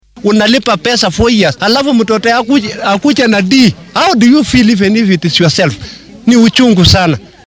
Mid ka mid ah waalidiinta Uasin Gishu ayaa ka hadlay natiijada ardayda ee aynan ku qancin.